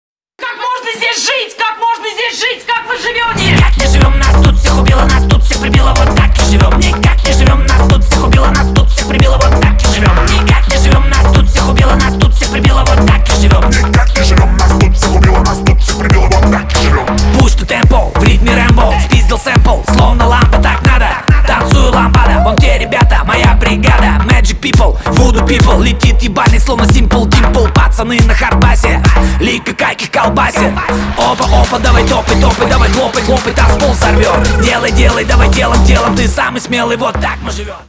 Внимание Ненормативная лексика!
Рэп и Хип Хоп
громкие # ритмичные